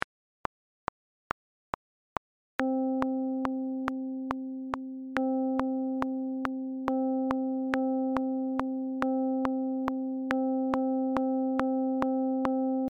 Note values in 6/8 time signature example
Note-values-in-68-audio-example-.mp3